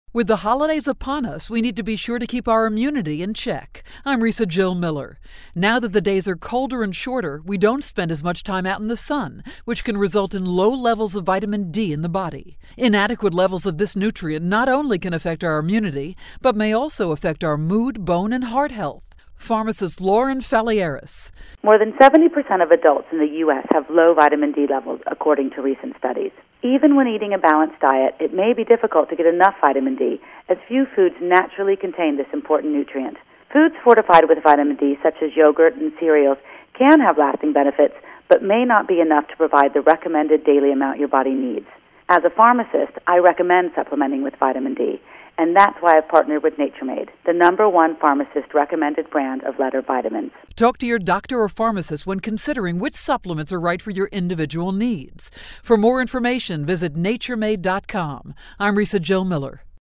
December 6, 2011Posted in: Audio News Release